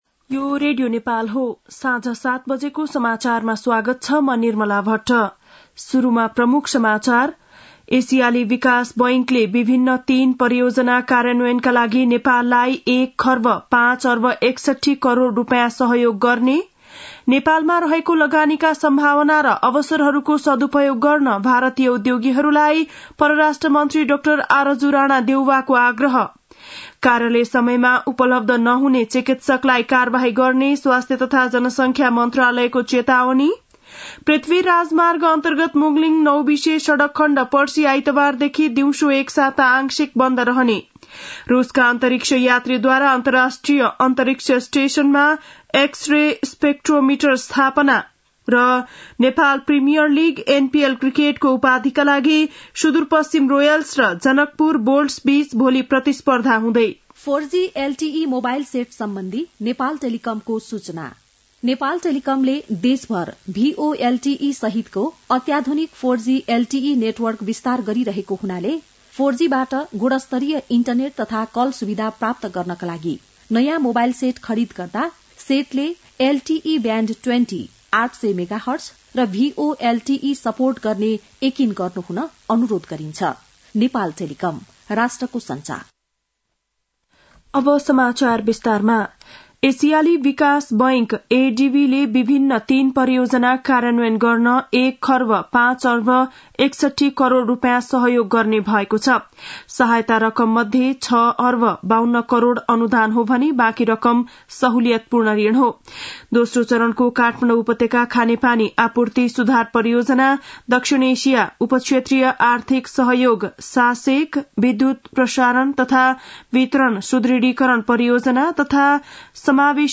बेलुकी ७ बजेको नेपाली समाचार : ६ पुष , २०८१
7-pm-nepali-news-9-05.mp3